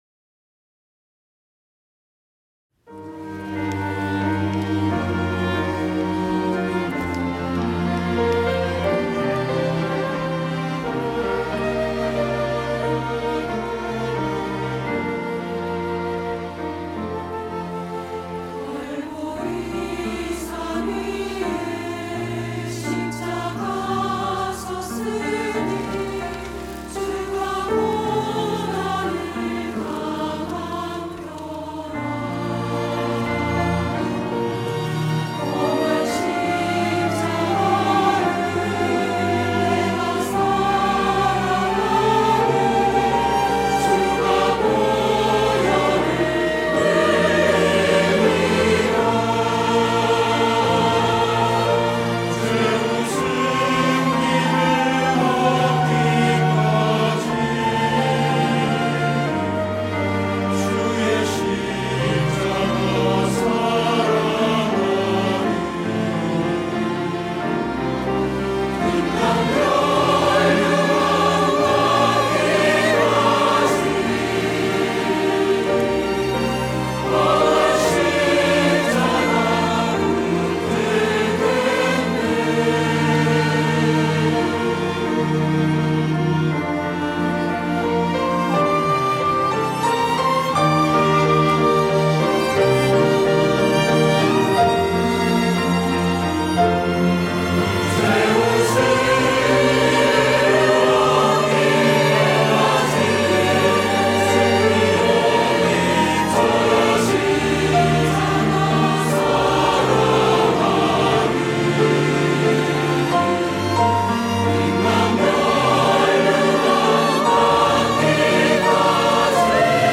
호산나(주일3부) - 갈보리산 위에
찬양대